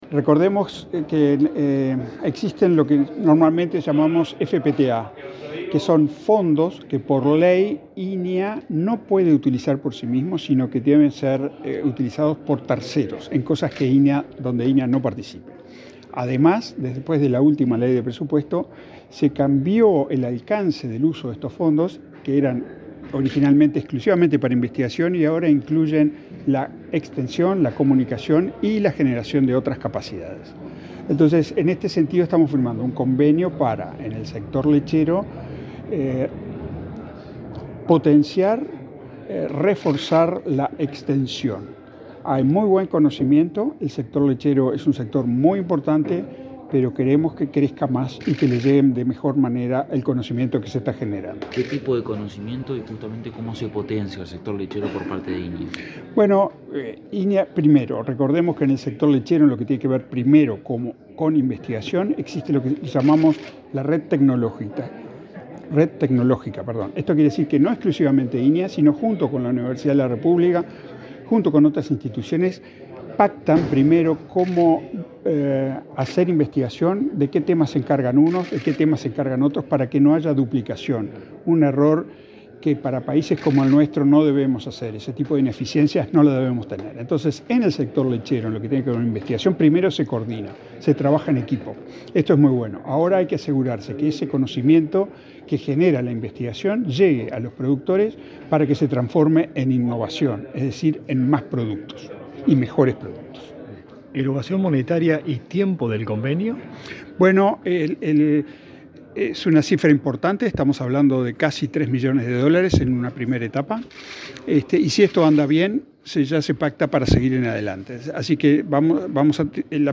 Declaraciones del titular del INIA, José Bonica
Este jueves 14 en la Expo Prado, el titular del Instituto Nacional de Investigación Agropecuaria (INIA), José Bonica, y su par del Instituto Nacional